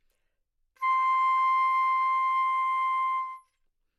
长笛单音 " 单音的整体质量 长笛 C6
描述：在巴塞罗那Universitat Pompeu Fabra音乐技术集团的goodsounds.org项目的背景下录制。
Tag: 纽曼-U87 C6 单注 多重采样 好声音 长笛